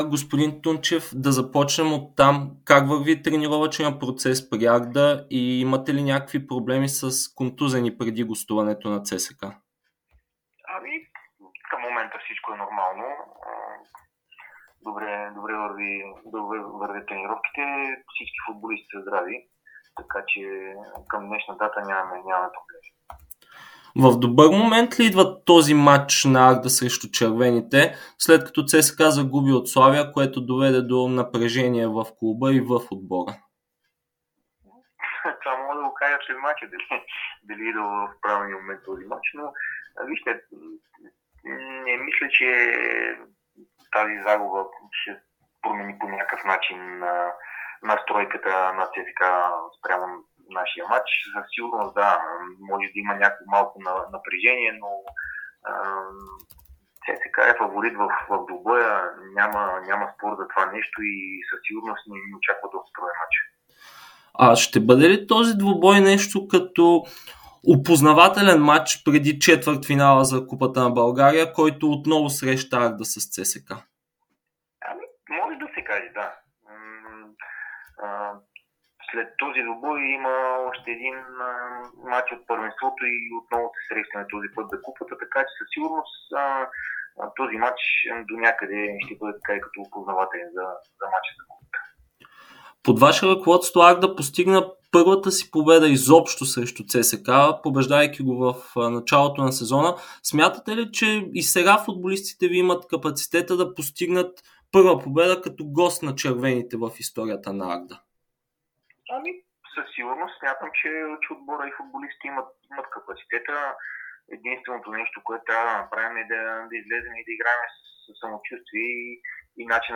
Старши треньорът на Арда – Александър Тунчев, даде ексклузивно интервю пред Дарик радио и dsport в дните преди гостуването на неговия отбор на ЦСКА от 21-ия кръг на българското първенство.